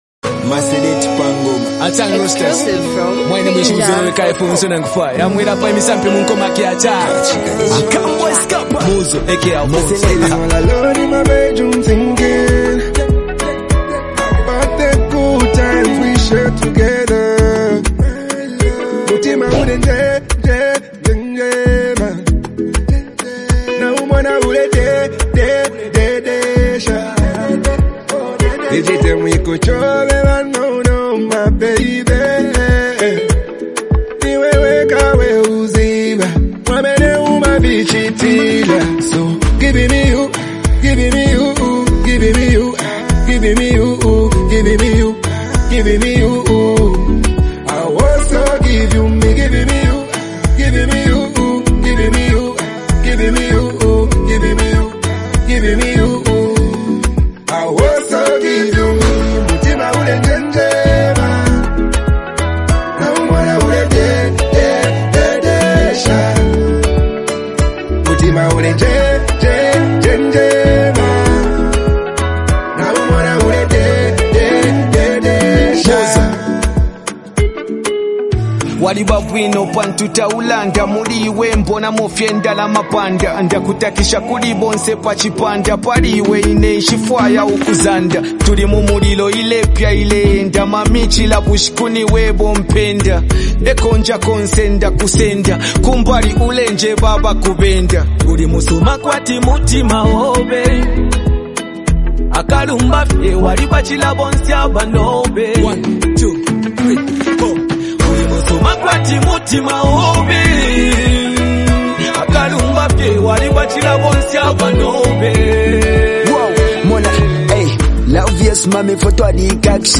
heartfelt vocals
reflective rap verse
smooth and expressive performance